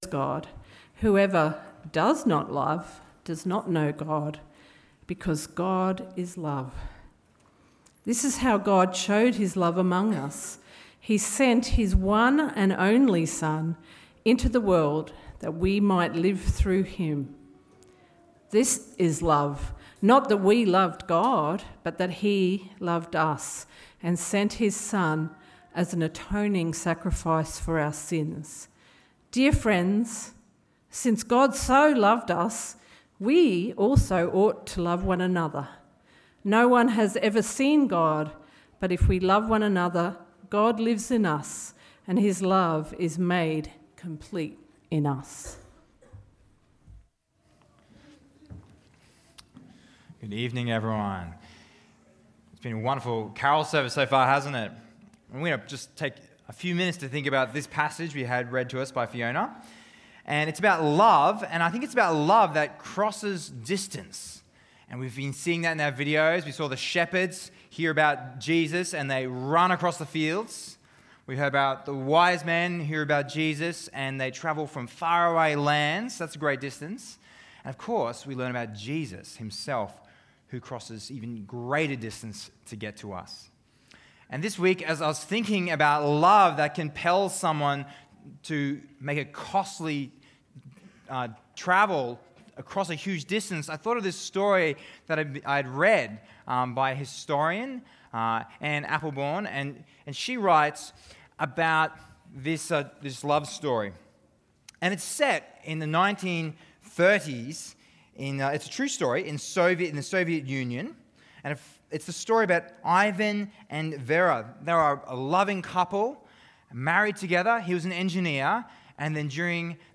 Sermons – Cairns Presbyterian Church
Dec-7th-PM-Carols-Service.mp3